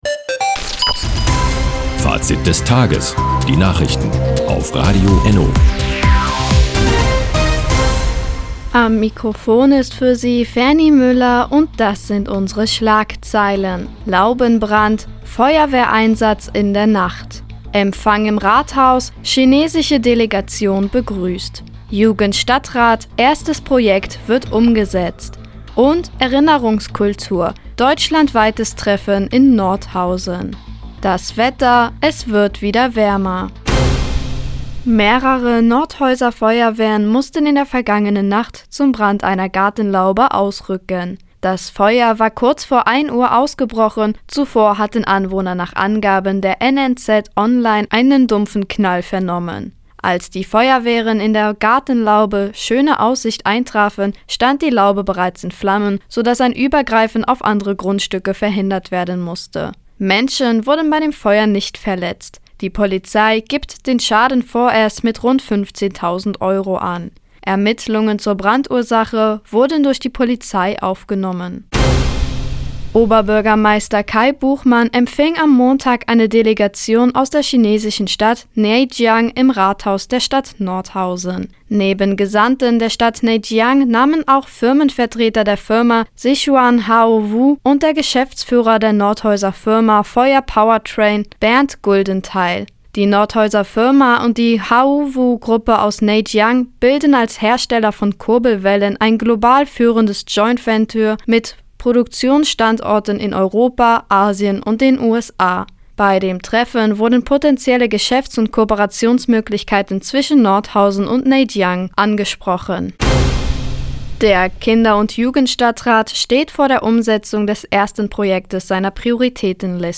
Die tägliche Nachrichtensendung ist jetzt hier zu hören...